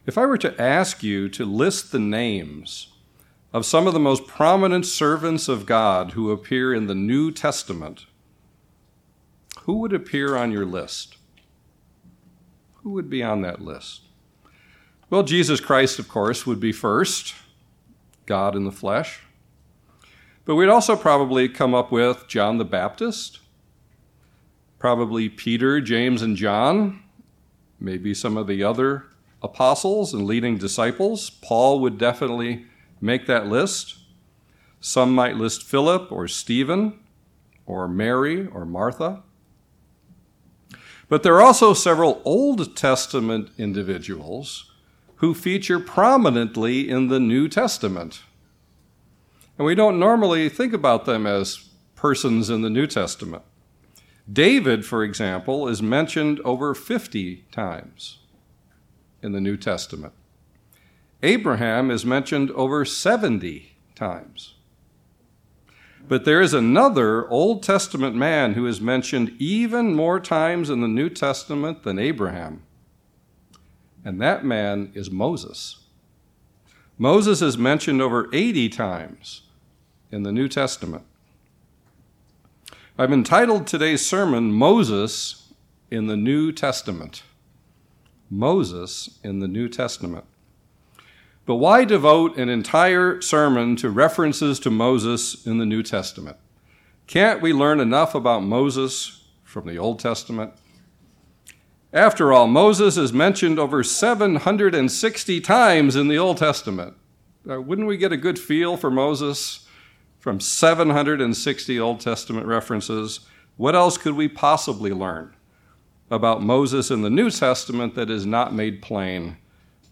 This sermon identifies five significant parallels between Moses and Jesus Christ that are expounded through the Old and New Testament scriptures. By piecing together the Old and New Testament references to Moses, we get a much fuller picture of how Moses’ roles in the Old Testament foreshadowed the even greater fulfillment of all those roles by Jesus Christ.